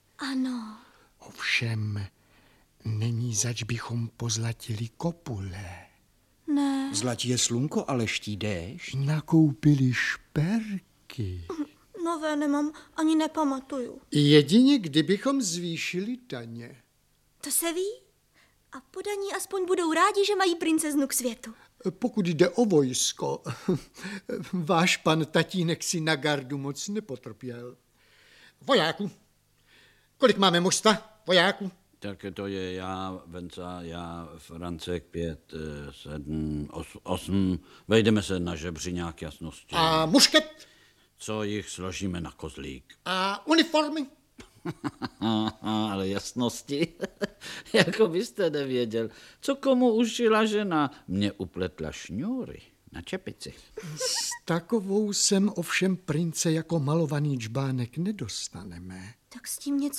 Audiobook
Read: Vlastimil Brodský